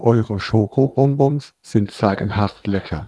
sample01-waveglow.wav